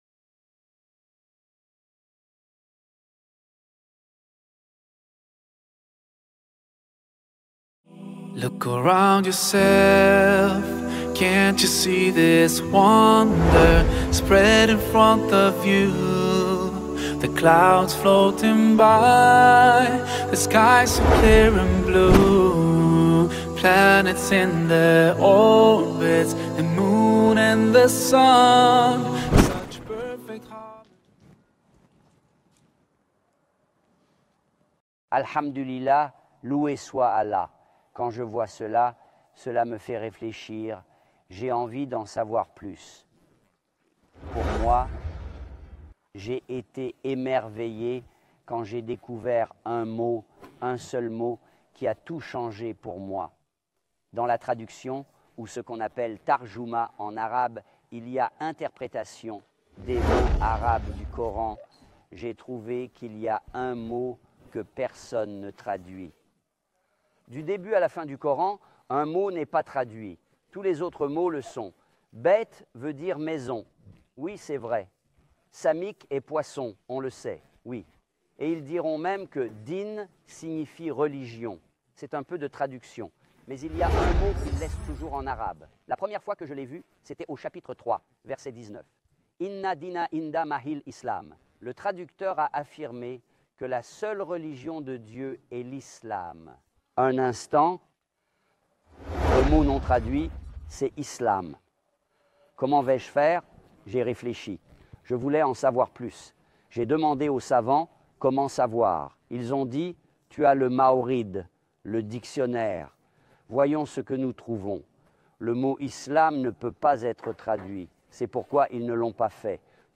filmée dans le décor de paysages pittoresques et de lieux historiques de Jordanie. Dans cet épisode, il explique ce que signifie l'Islam.